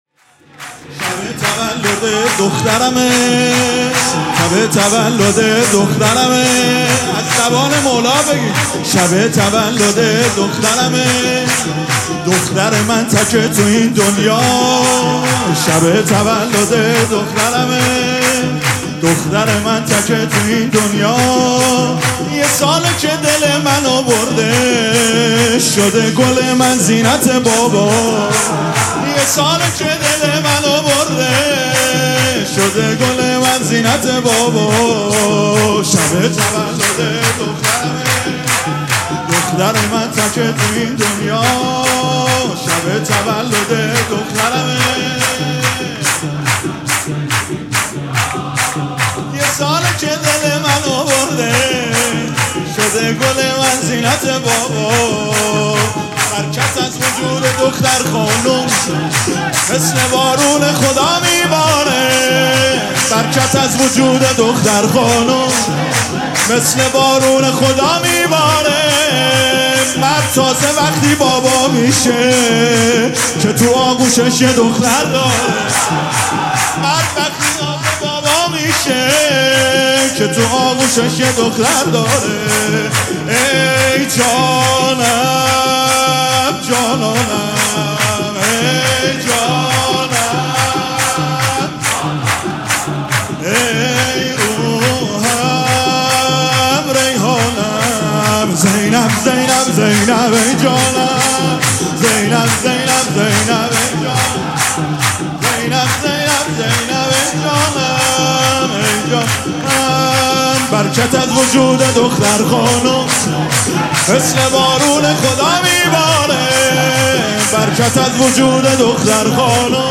مراسم جشن ولادت حضرت زینب (سلام الله علیها)
سرود
مداح